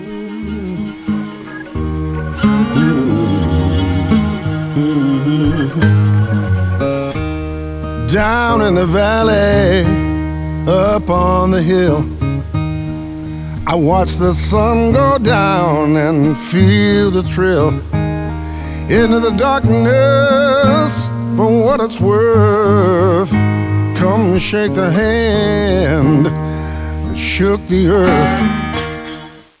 Vocals, harmonica, spoons, background vocals
Piano, organ, Werlitzer
Drums and percussion
Recorded at Jukasa Studios, August 2014